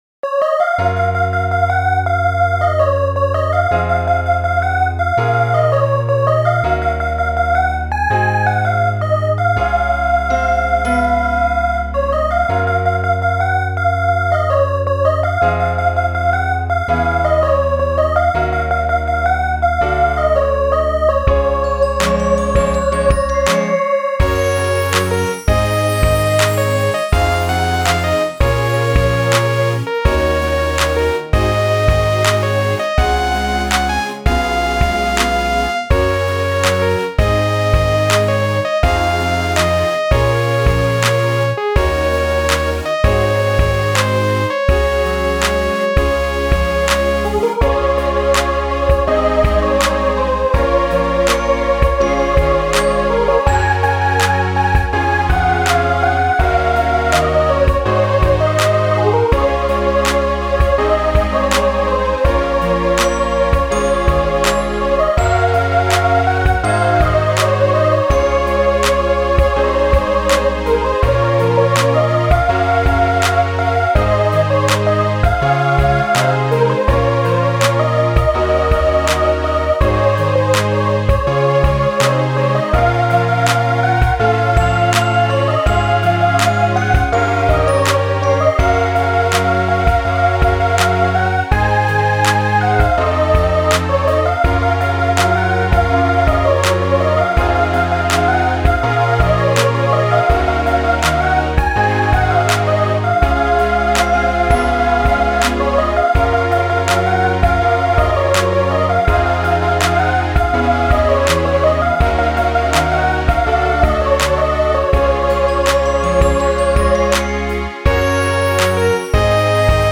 エンディングかな？